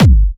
VEC3 Bassdrums Trance 70.wav